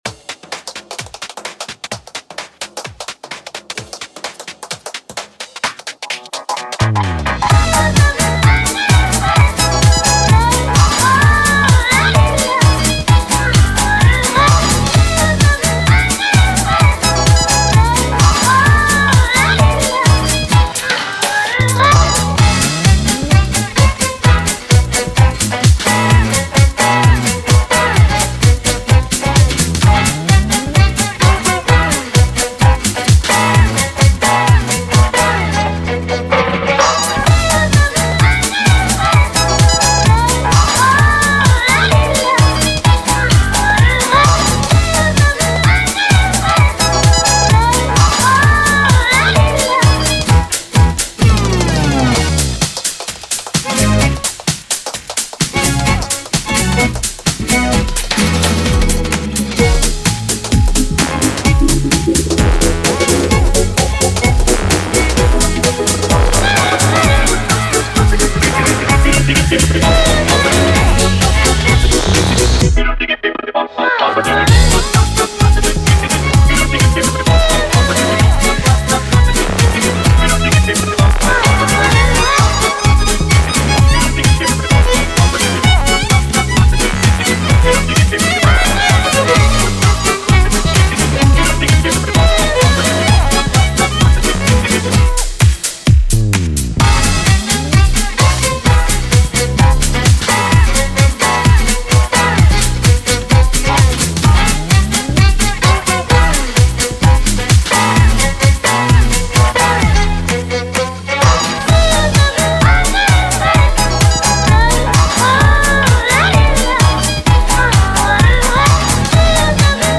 BPM129
Audio QualityMusic Cut